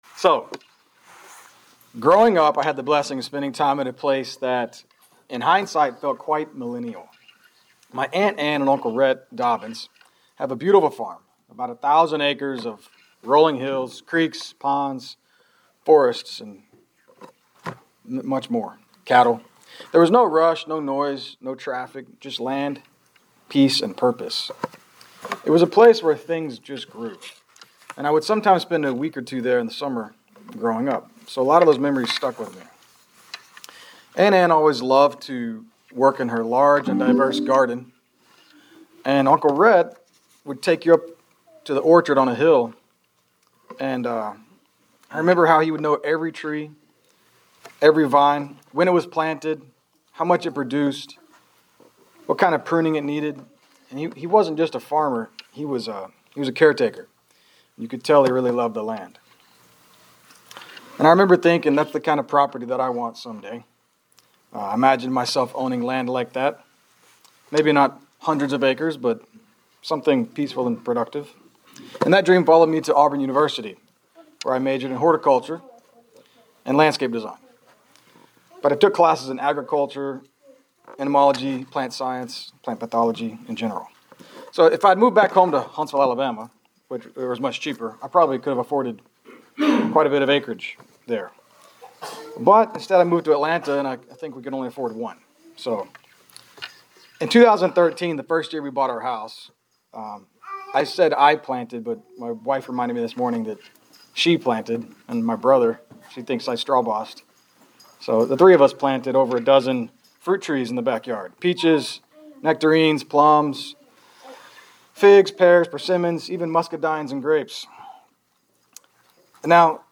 Atlanta Georgia home congregation.